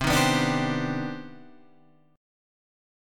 CmM9 chord {8 6 9 7 8 8} chord